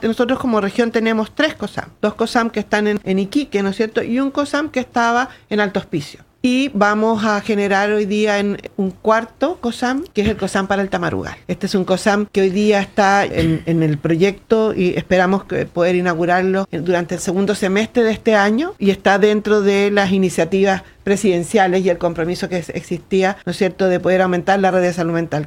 La directora del Servicio de Salud de Tarapacá, María Paz Iturriaga, anunció en entrevista con Radio Paulina importantes avances en infraestructura de salud mental en la región, incluyendo la creación de un nuevo COSAM en la provincia del Tamarugal, la reubicación de centros existentes y la modernización de instalaciones.